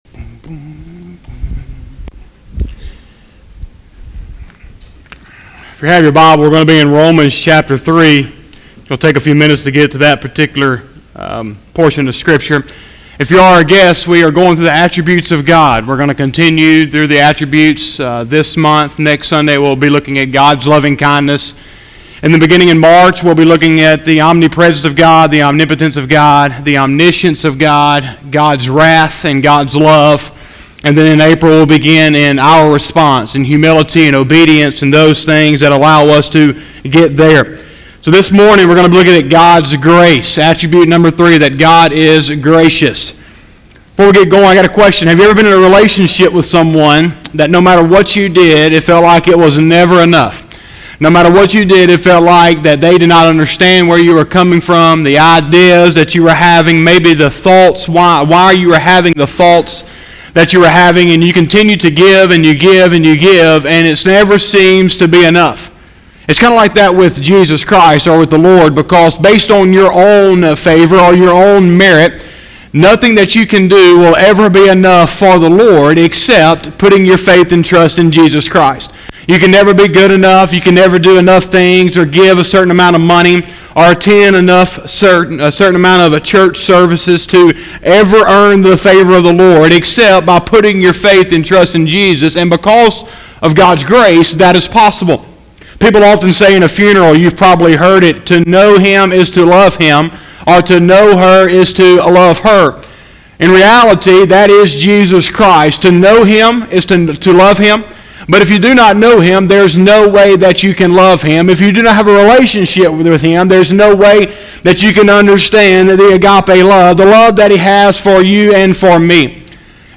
02/16/2020 – Sunday Morning Service